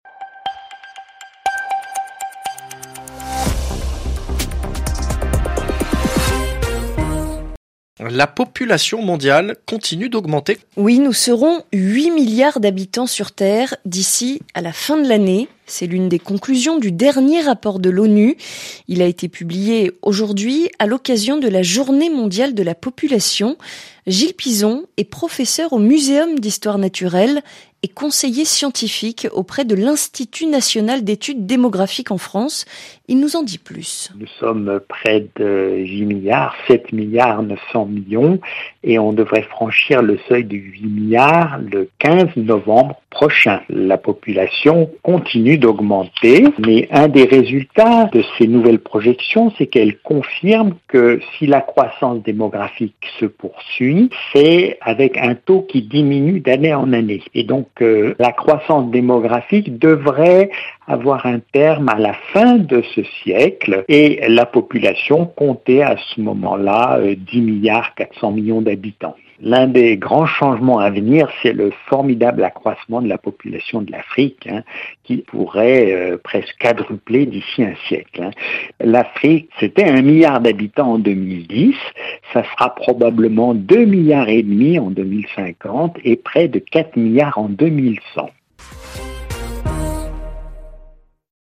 Extrait du Journal en français facile du 11/07/2022 (RFI)